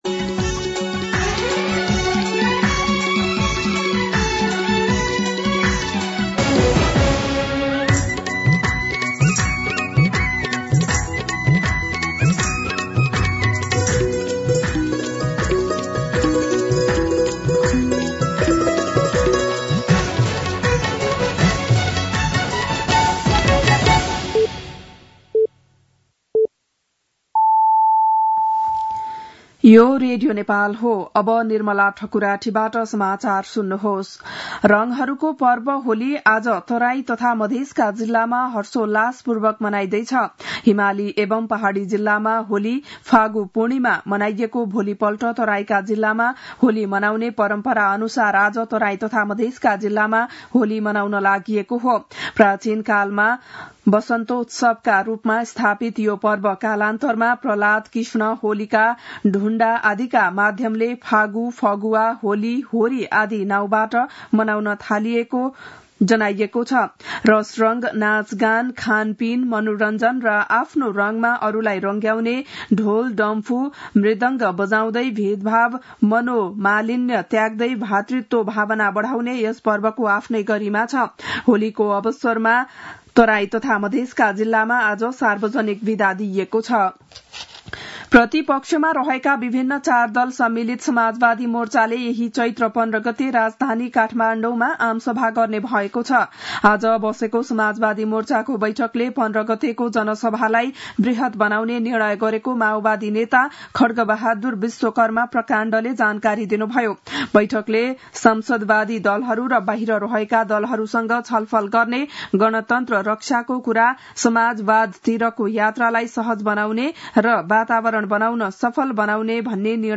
बिहान ११ बजेको नेपाली समाचार : १ चैत , २०८१